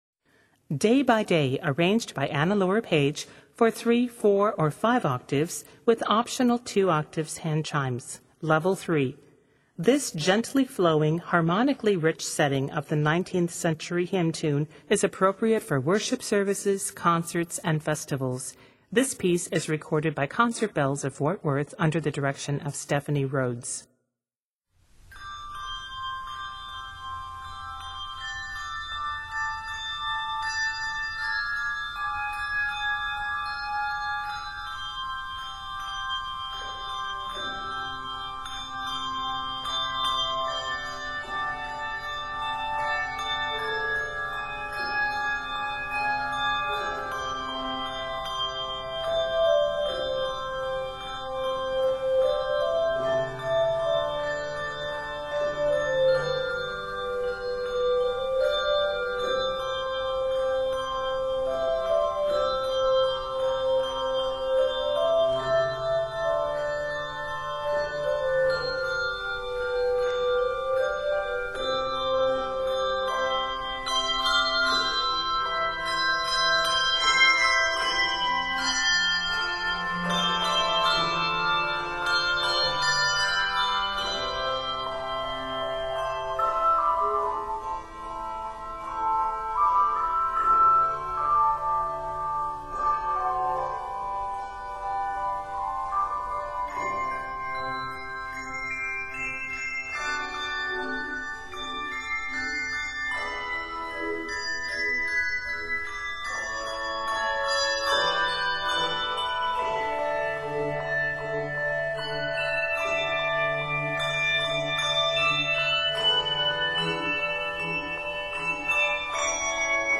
gently flowing, harmonically rich setting
hymntune